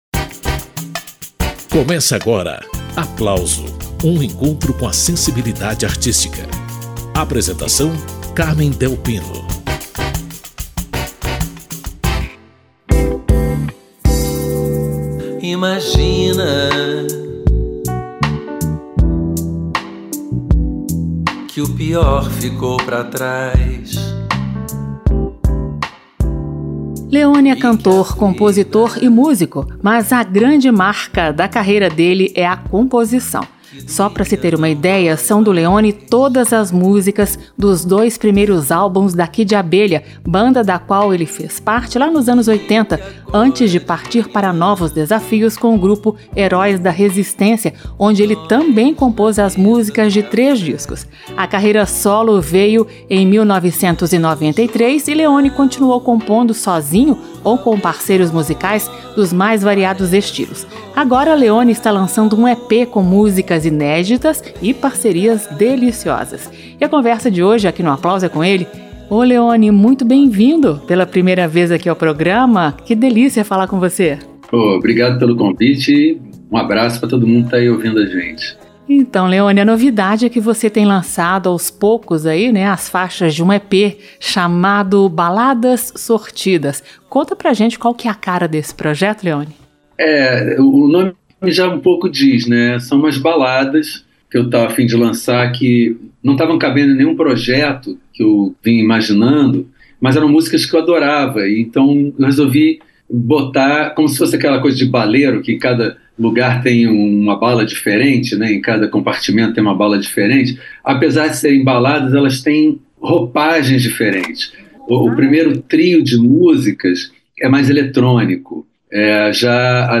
Nesta edição do programa Aplauso, Leoni comenta essas novidades, mas também relembra composições dele que ganharam corações e mentes, nos anos 1980, com as bandas Kid Abelha e Heróis da Resistência.